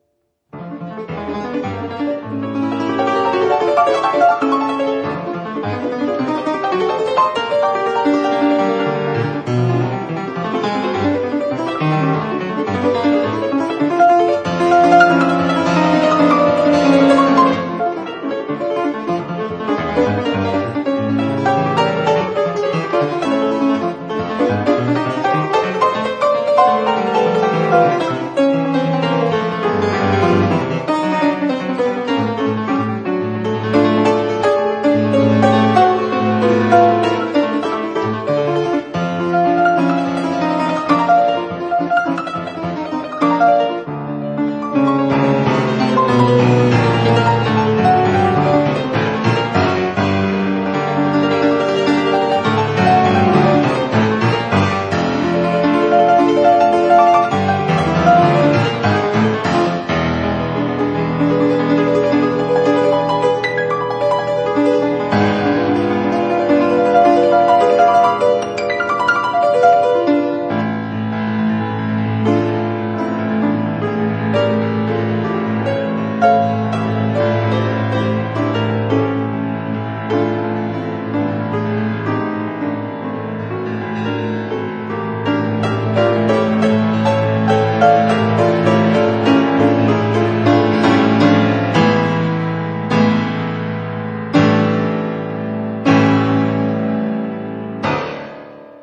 在古鋼琴上秀活力，相當吸引人。